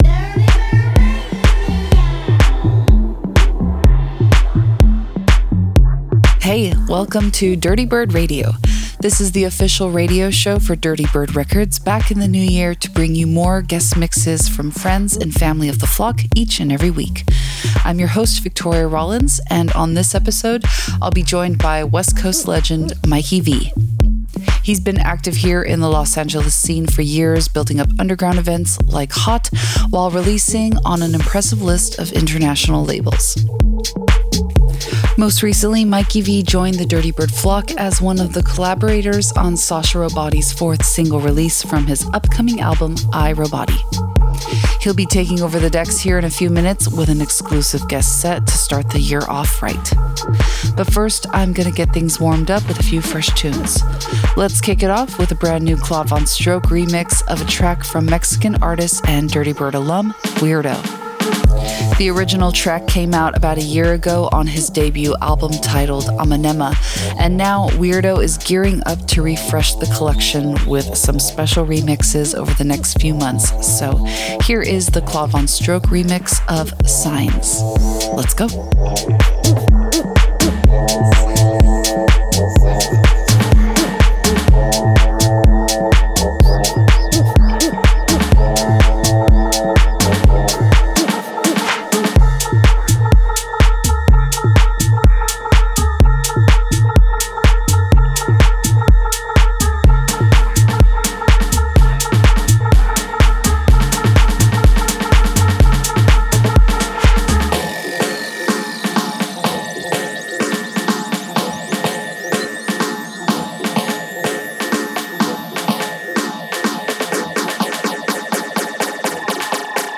house
warm-up set